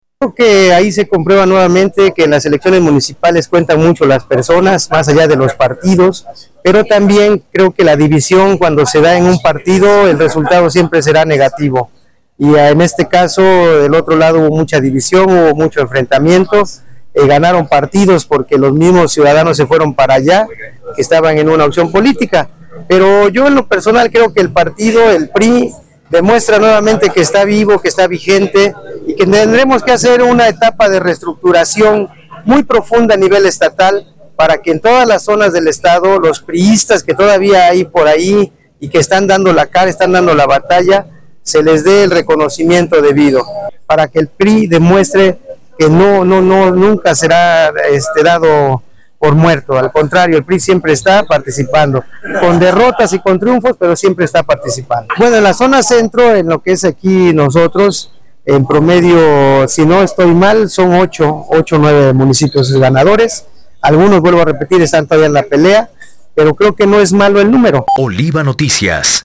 En entrevista, señaló que esta polarización afectó directamente los resultados electorales en diversos municipios, advirtiendo que cada partido deberá entrar en un proceso de reestructuración interna.